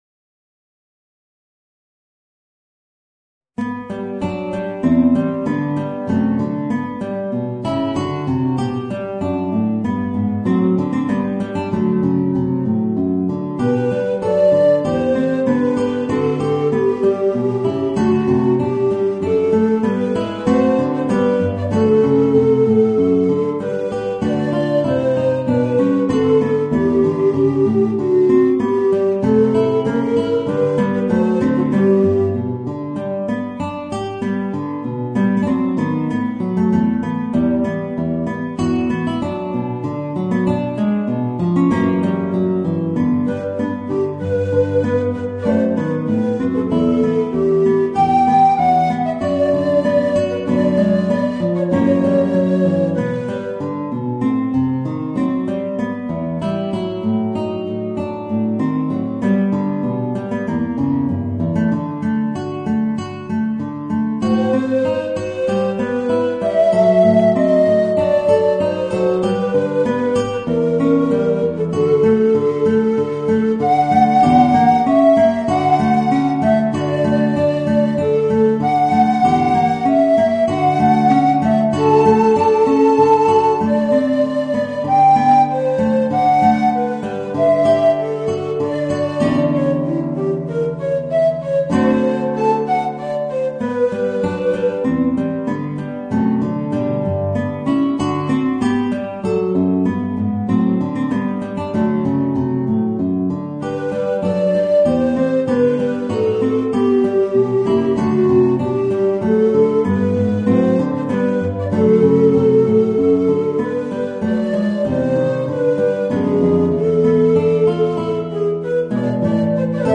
Voicing: Tenor Recorder and Guitar